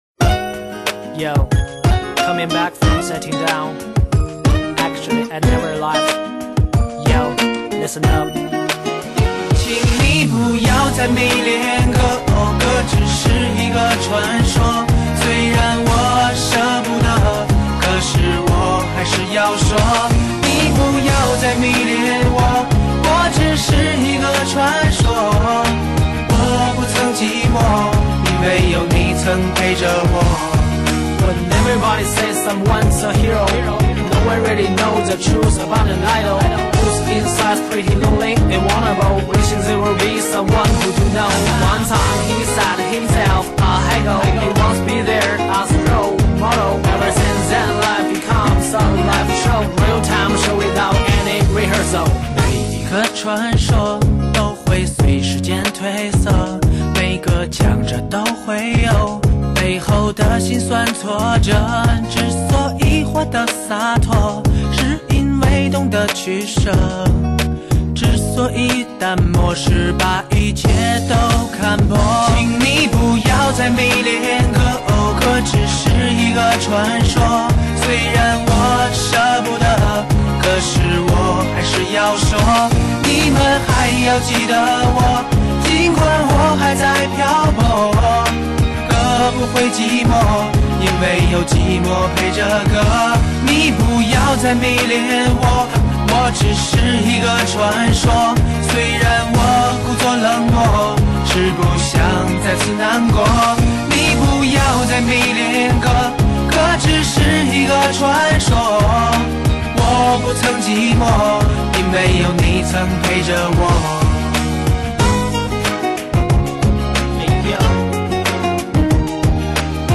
用最纯净的嗓音，描绘情归何处……